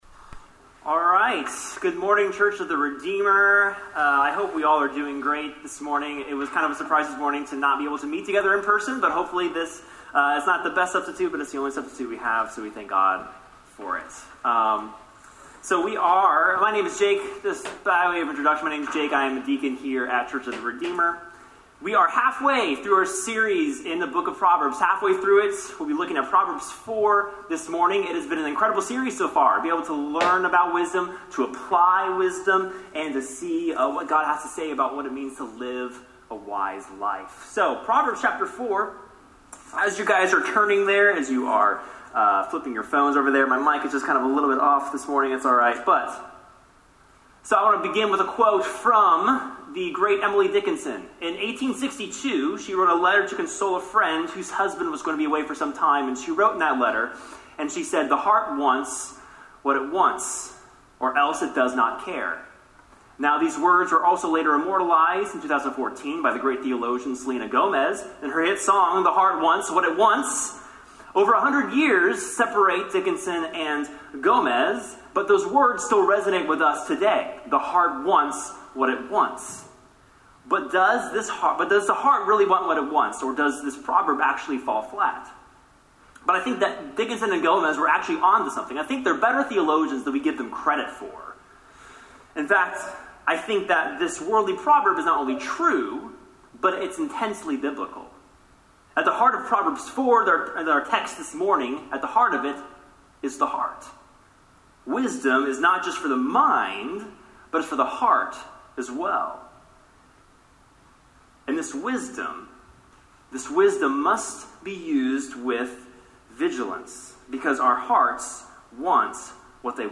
Sermon on Proverbs 4